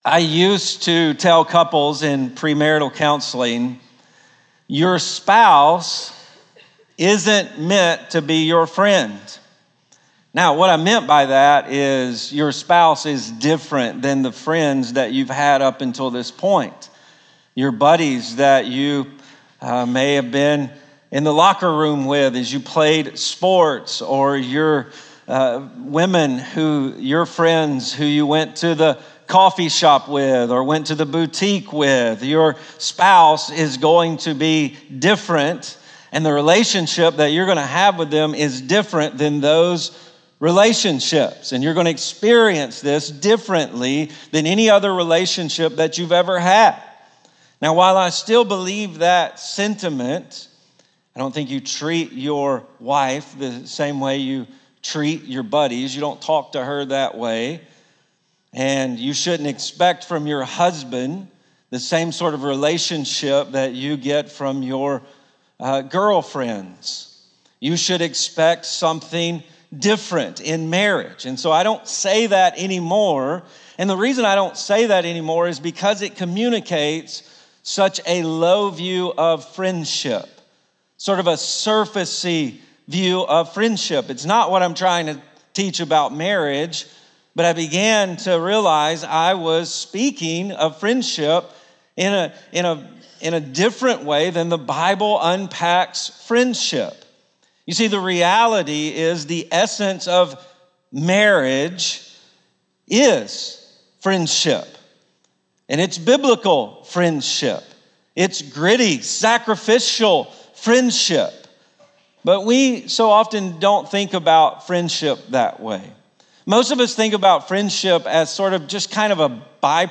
Ashland Church Sermons The Fear of the Lord in a World of Fools (Proverbs 13:20) Feb 16 2025 | 00:43:06 1x 00:00 / 00:43:06 Subscribe Share Spotify RSS Feed Share Link Embed